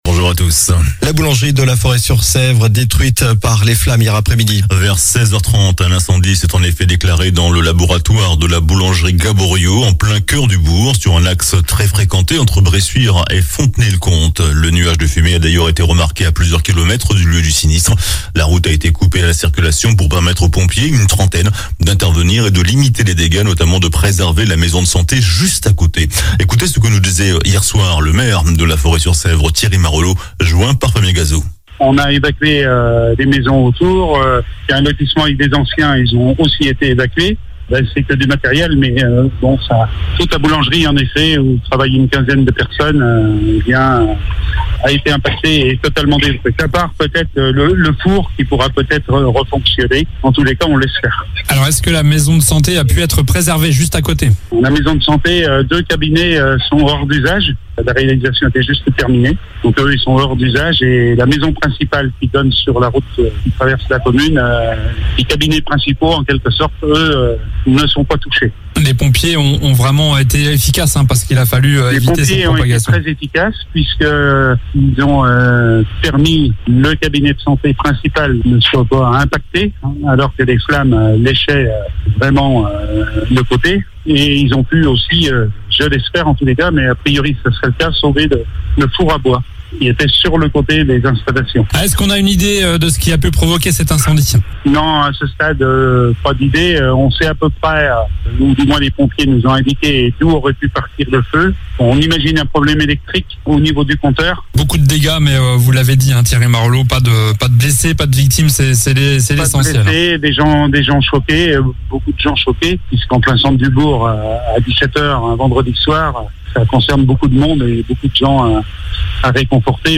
JOURNAL DU SAMEDI 24 SEPTEMBRE